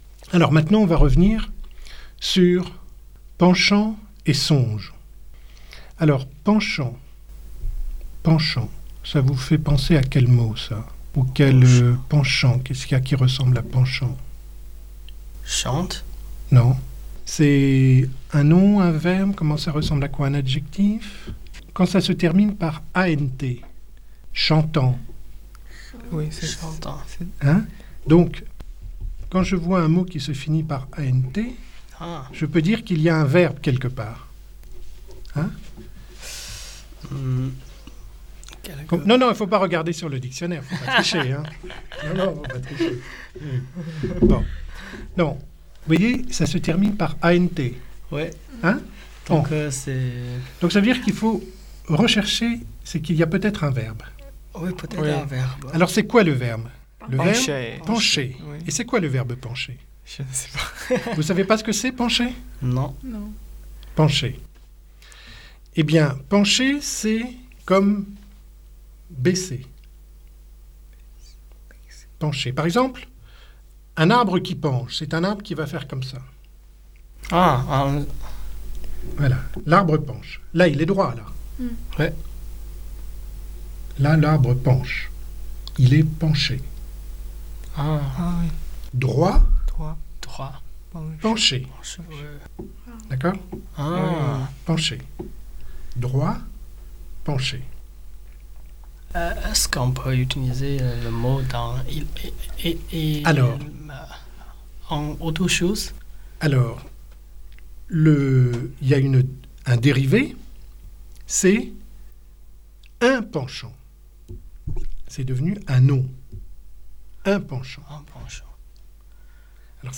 Des étudiants étudiants chinois de l’Université Lille 1 sont invités à enregistrer leurs conversations en français lors du Café-Langues organisé par la Maison des Langues de Lille 1 le 22 février 2012.